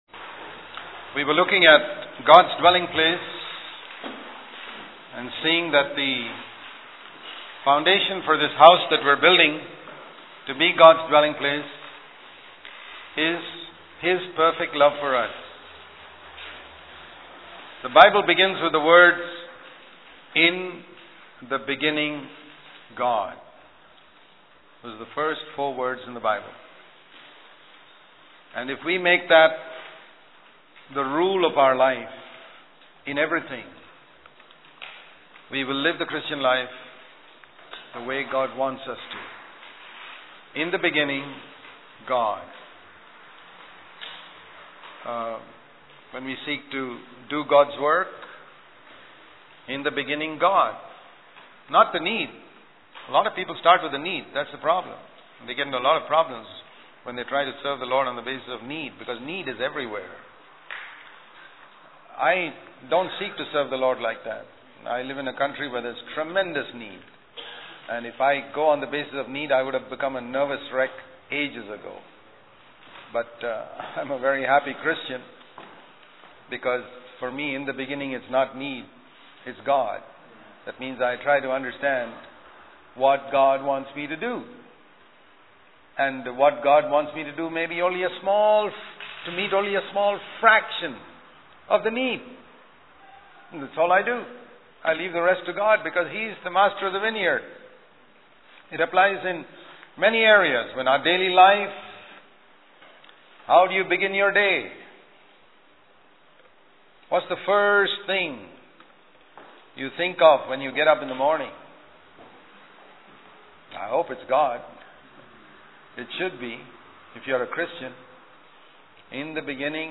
In this sermon, the speaker emphasizes the importance of allowing God to work in our hearts before we can effectively serve Him. He highlights the need for obedience and surrender to God's will, rather than relying on our own knowledge and intellect.